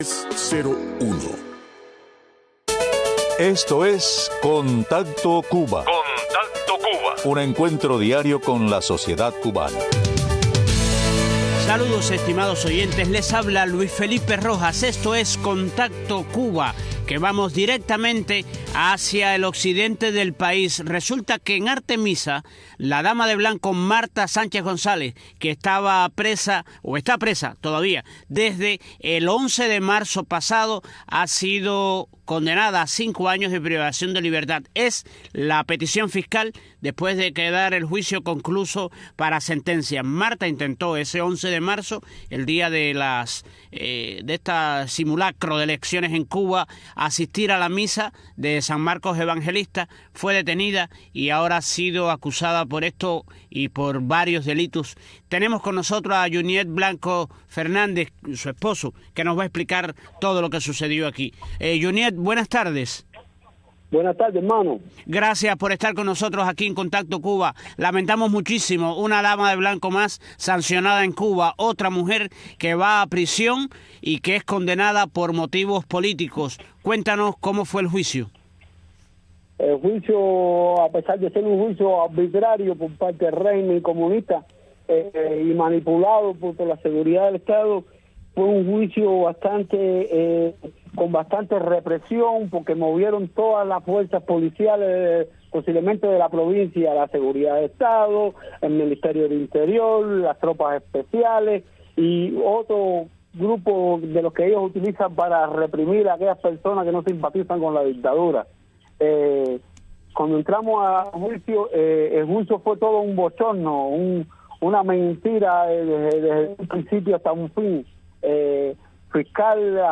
También ofrecemos entrevistas con varios bicitaxistas que han sido multados “arbitrariamente”, según sus propios testimonios.